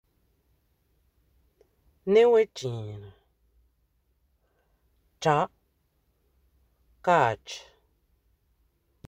Accueil > Prononciation > tr > tr